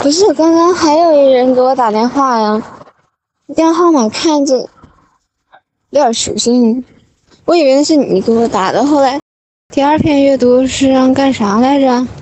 Emocjonalny głos wyznania miłości: naturalne AI do treści romantycznych
Doświadcz wrażliwego, naturalnie brzmiącego głosu AI zaprojektowanego do intymnych wyznań, romantycznego opowiadania historii i pełnych emocji dialogów.
Tekst na mowę
Wrażliwy ton
Niuanse oddechu